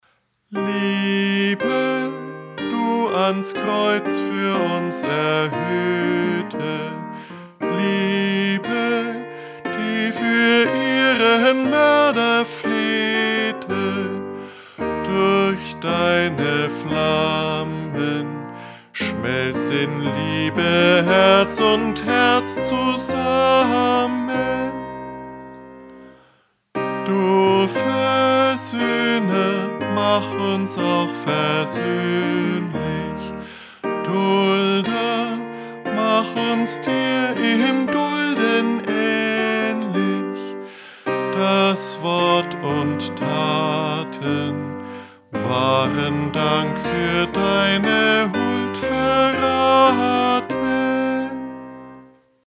Liedtext: 1825, Karl Bernhard Garve (1763-1841)
Melodie: 1647, Johann Crüger (1598-1663)
Liedvortrag